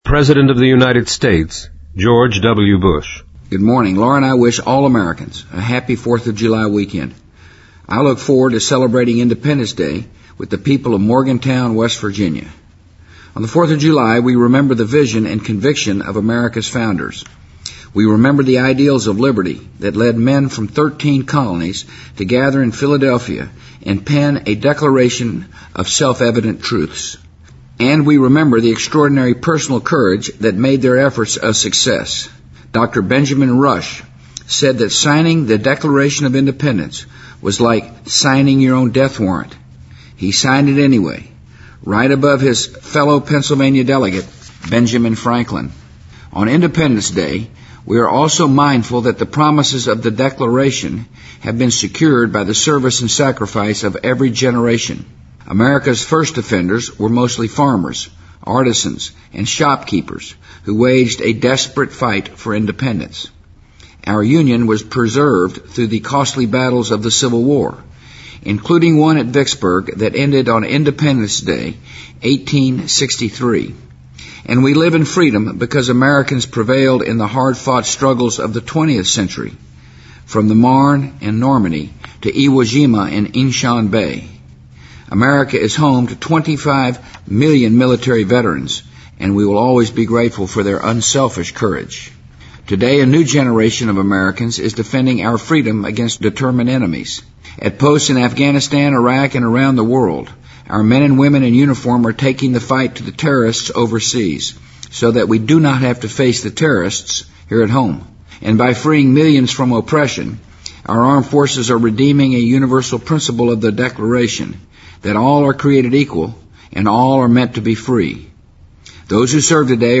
【美国总统电台演说】2005-07-02 听力文件下载—在线英语听力室